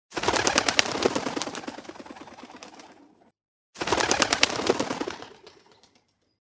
Звуки голубей
Голос голубя и его воркование